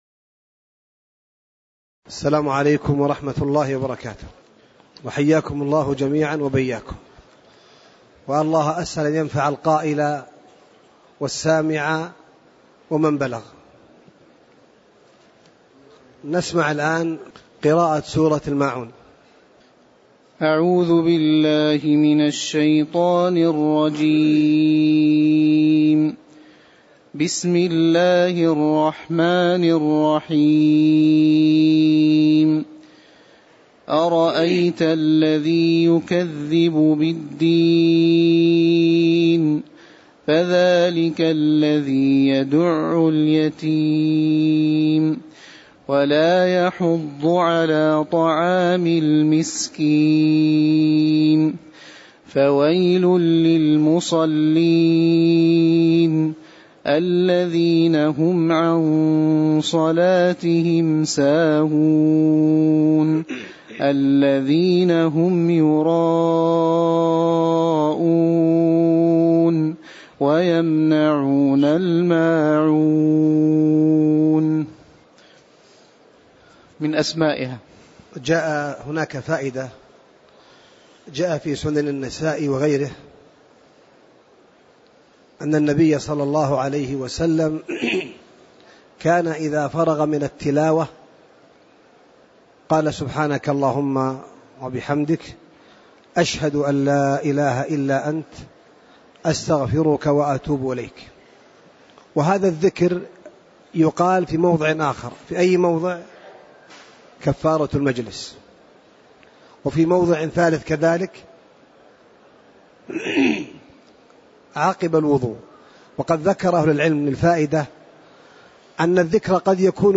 تاريخ النشر ٦ جمادى الآخرة ١٤٣٧ هـ المكان: المسجد النبوي الشيخ